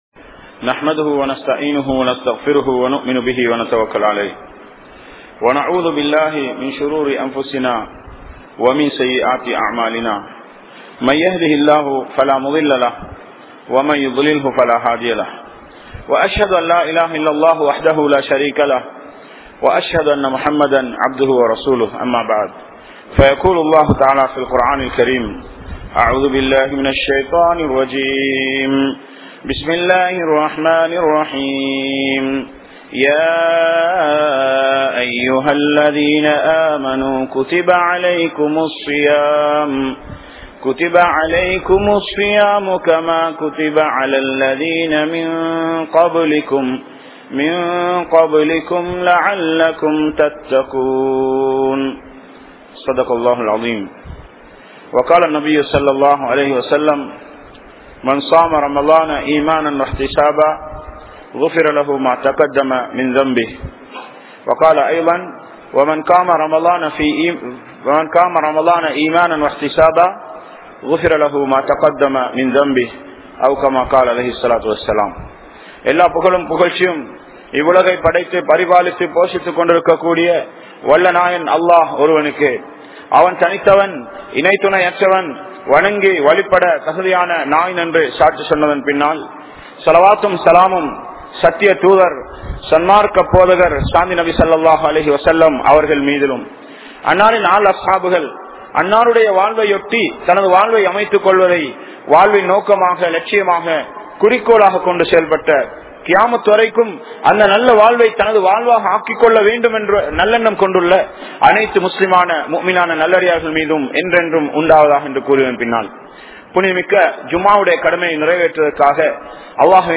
Ramalaanilum Paavaththai Vidaathavarhal (ரமழானிலும் பாவத்தை விடாதவர்கள்) | Audio Bayans | All Ceylon Muslim Youth Community | Addalaichenai
Kanampittya Masjithun Noor Jumua Masjith